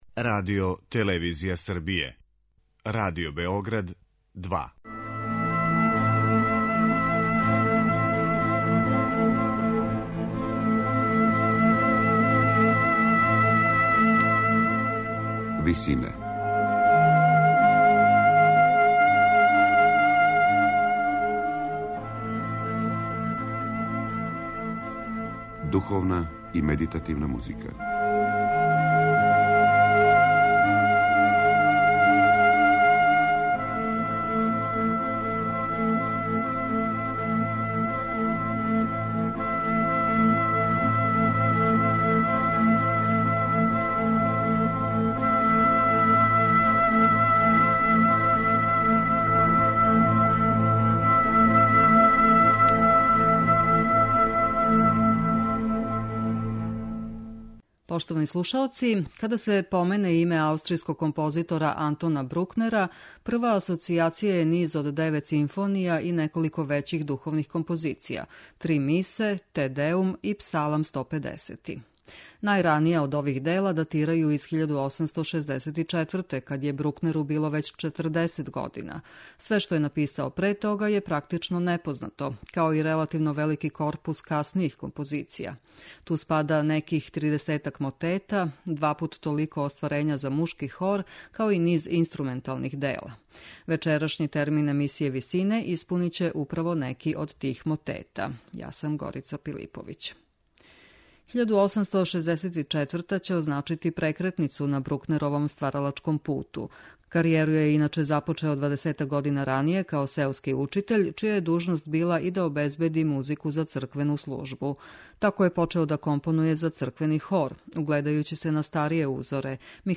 Духовна музика Антона Брукнера